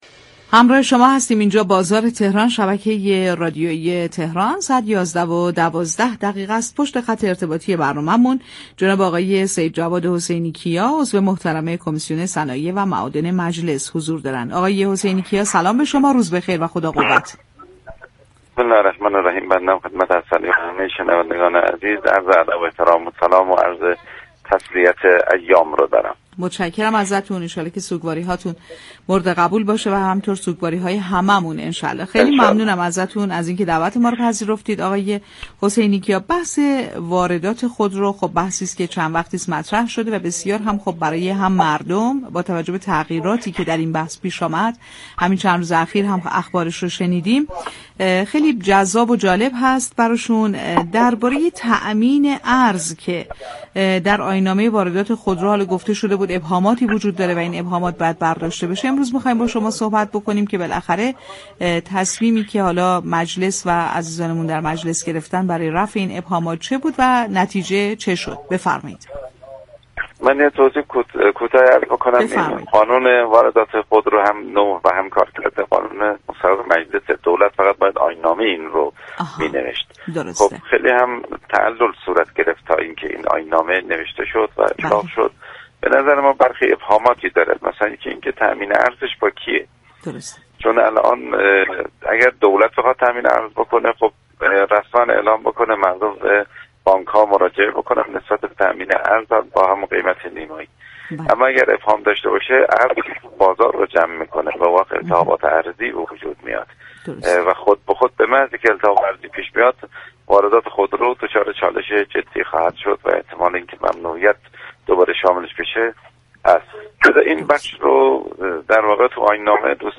سیدجواد حسینی‌كیا عضو كمیسیون صنایع و معادن مجلس در گفت و گو با «بازار تهران»